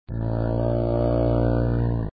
moaaan.mp3